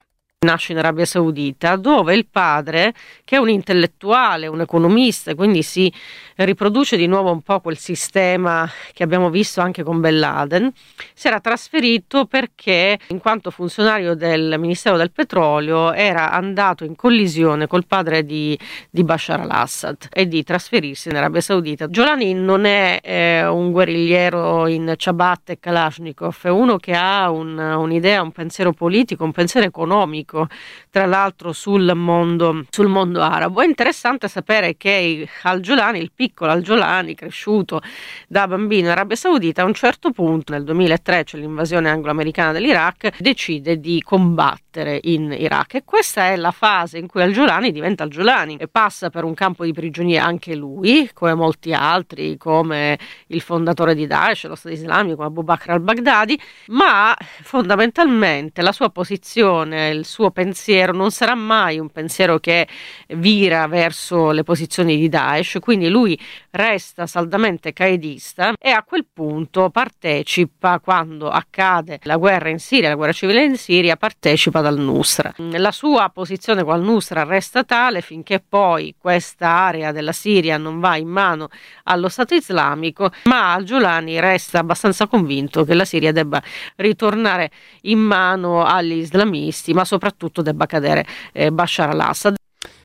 giornalista esperta dell’area